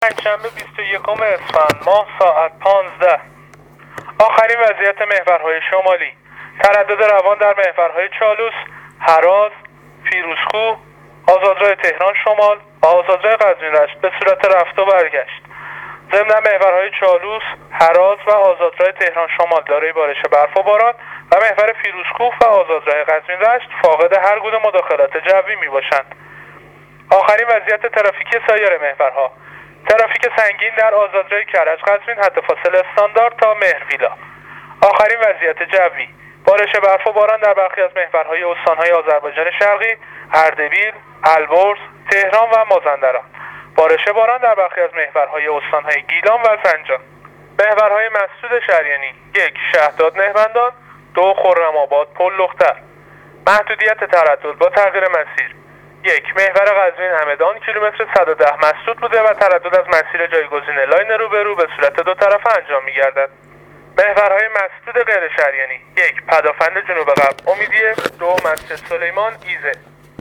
گزارش رادیو اینترنتی از آخرین وضعیت ترافیکی جاده‌ها تا ساعت ۱۵ بیست ویکم اسفند